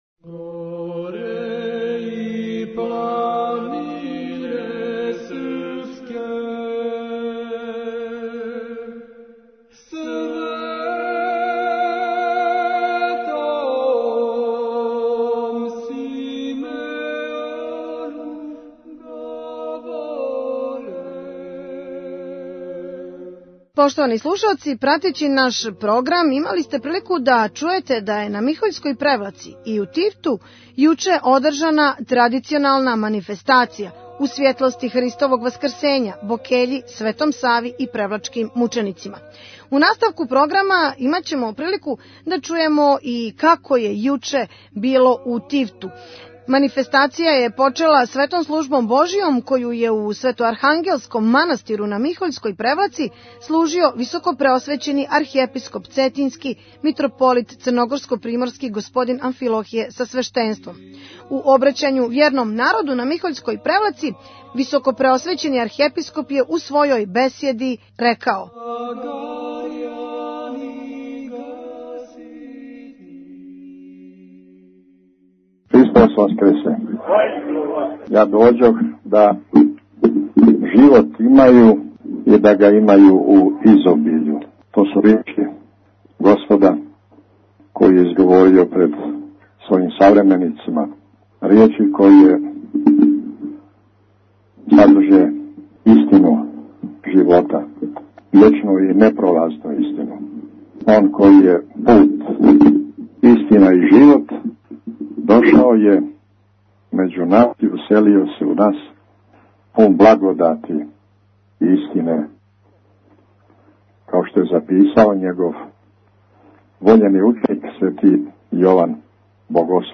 Манифестација је почела Светом службом Божјом, коју је у Светоархангелском манастиру на Михољској Превлаци служио Високопреосвећени Архиепископ цетињски Митрополит црногорско-приморски Господин Амфилохије са свештенством.